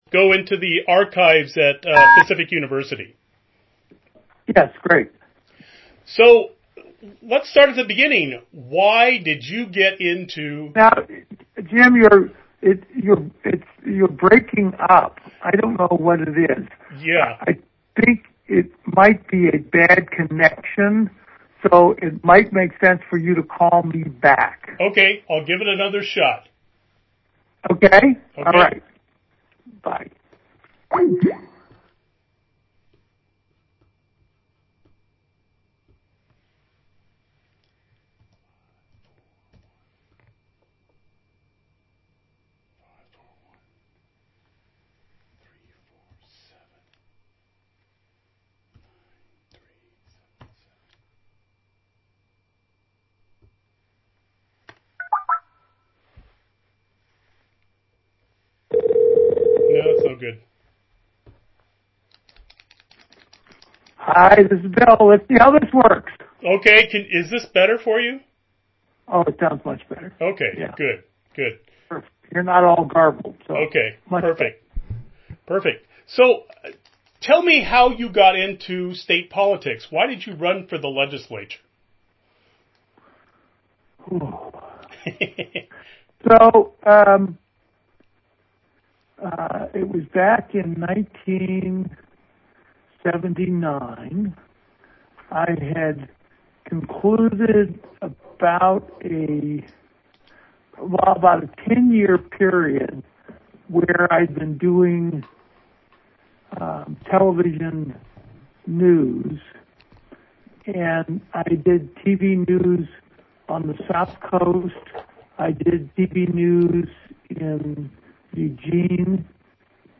9a14f3e4cd493dec1c2f1e6f97c95bdeccef7867.mp3 Title Bill Bradbury interview on Atiyeh Description An interview of former Oregon Secretary of State and legislator Bill Bradbury, on the topic of Oregon's Governor Vic Atiyeh, recorded on January 19, 2016.
Note: This interview was recorded over a telephone connection, and the audio quality of Bradbury's voice is poor.